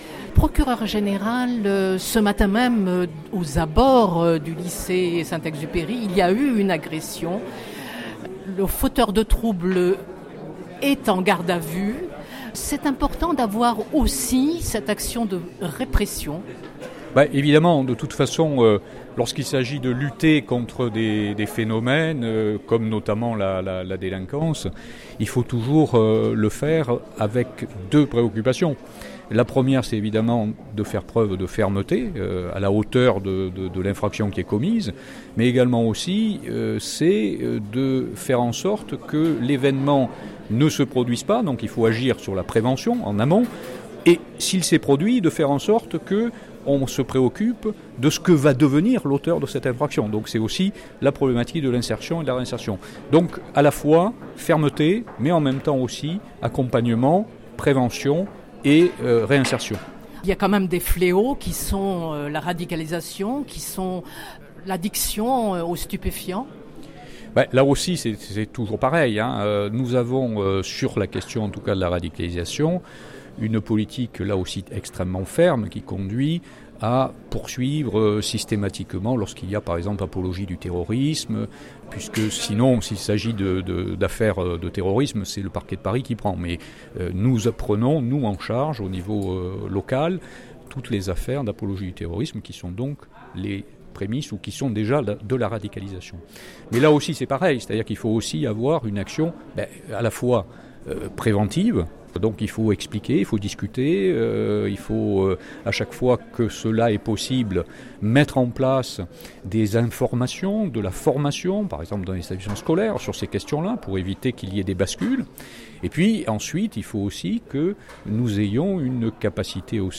Nous avons posé la question à Robert Gelli, procureur général près la Cour d’appel d’Aix-en-Provence.